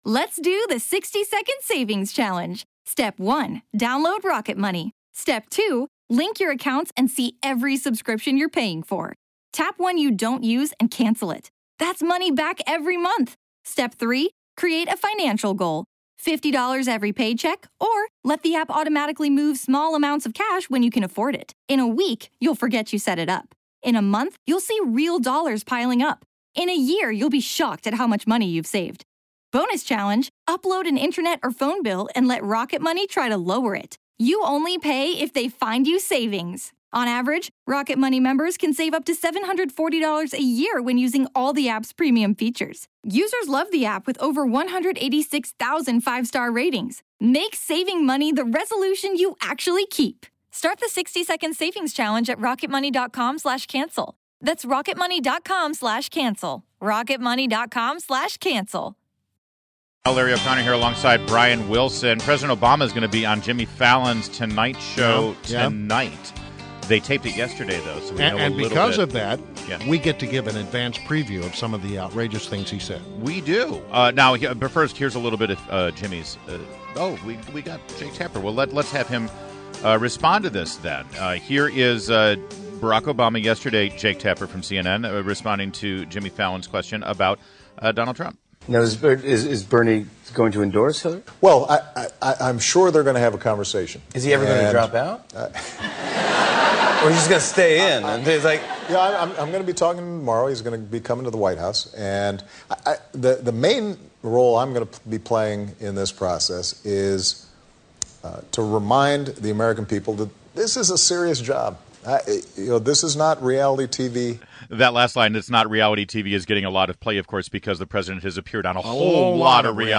WMAL Interview - JAKE TAPPER - 06.09.16
INTERVIEW -- JAKE TAPPER -- Anchor of "The Lead" and "State of the Union" on CNN